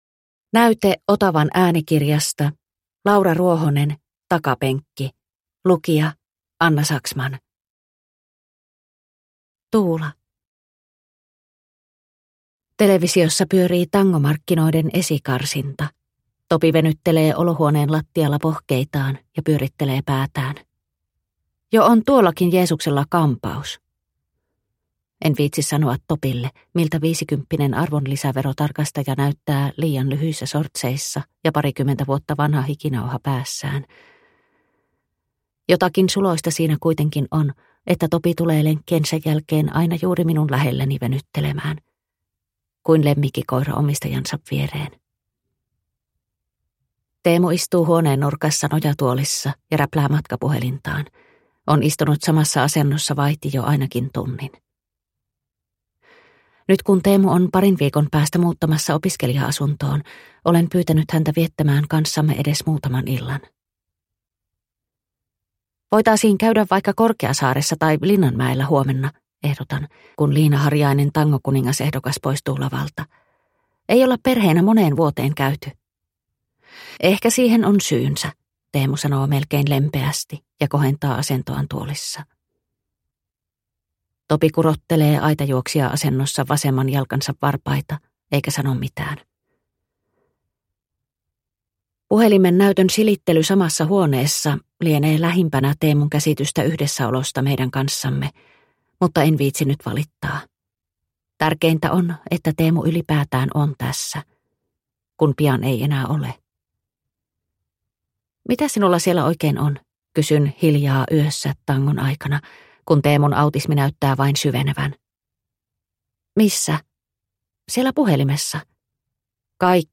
Takapenkki – Ljudbok – Laddas ner